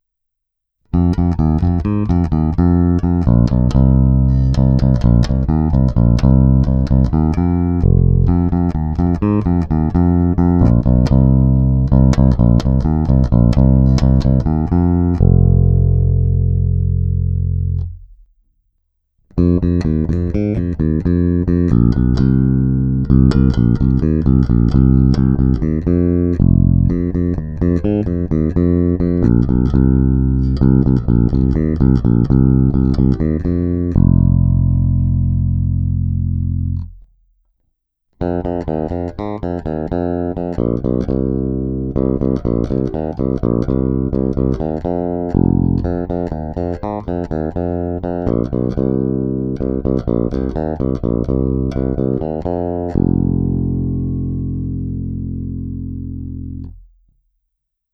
Není-li uvedeno jinak, následující nahrávky jsou provedeny rovnou do zvukové karty, jen normalizovány, jinak ponechány bez úprav.
Hráno vždy nad aktivním snímačem, v případě obou pak mezi nimi.